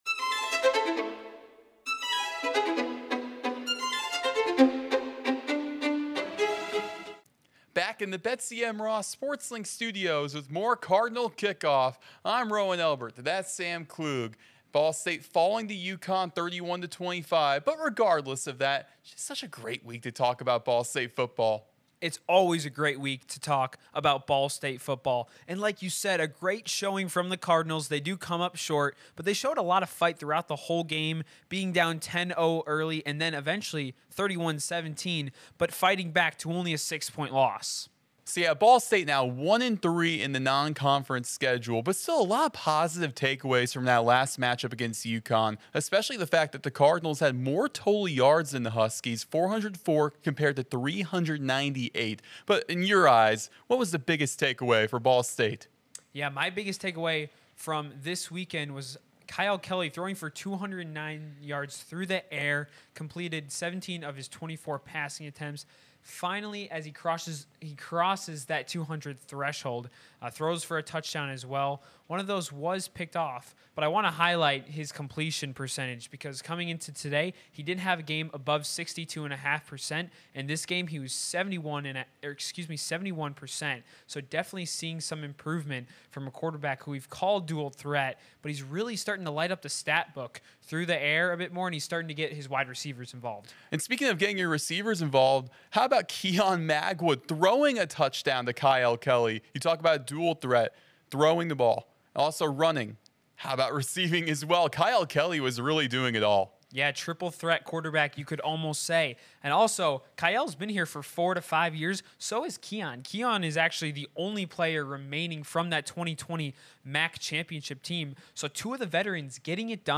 Each week go inside Ball State Football with exclusive interviews, feature stories, and discussion.